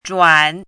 zhuǎn
zhuǎn.mp3